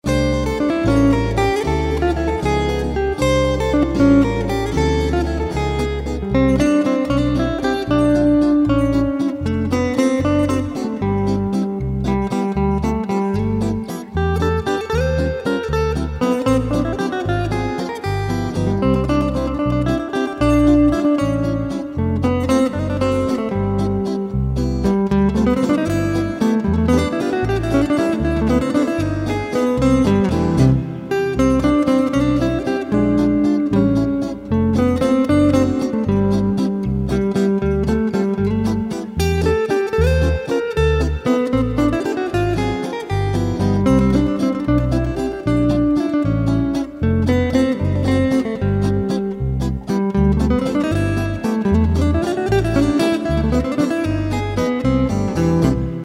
Valzer per chitarra in stile francese